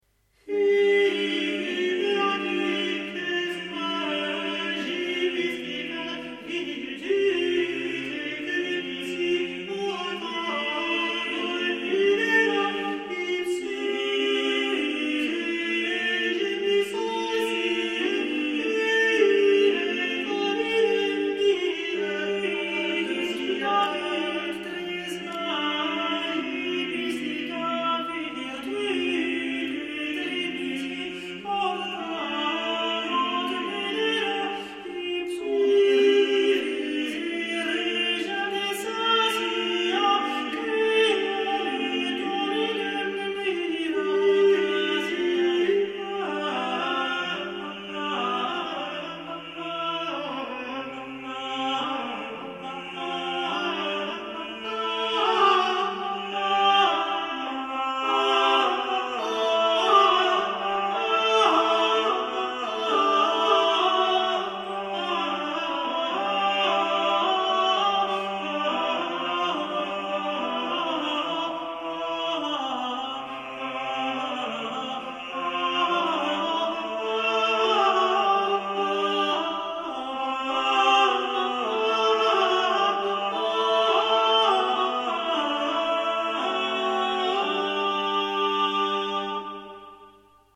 Huic ut placuit — (vocale) | Alleluia nativitas | Orlando consort | Metronome 1990